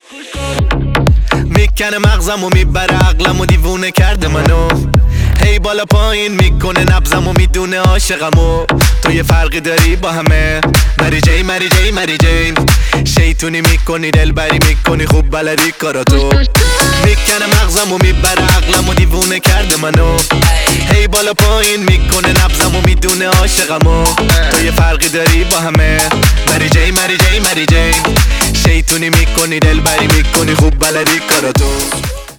Танцевальные
восточные